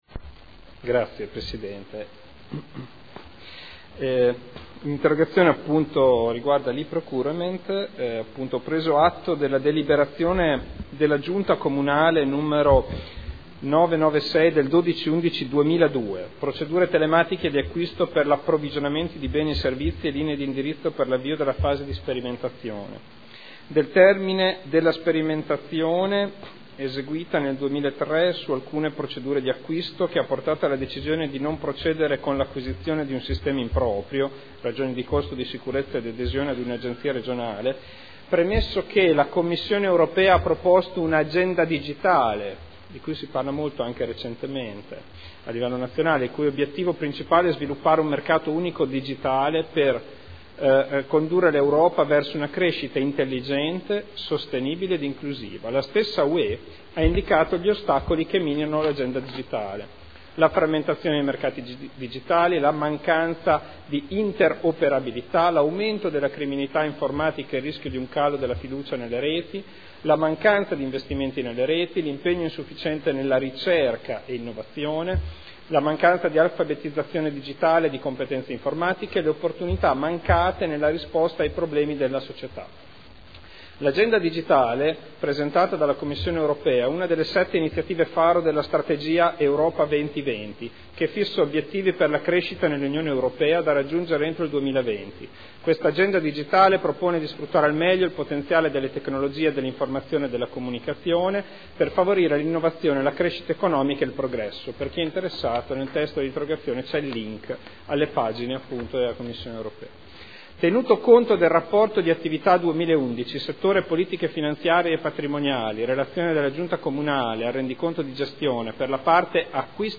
Interrogazione del consigliere Ricci (Sinistra per Modena) avente per oggetto: “E-procurement”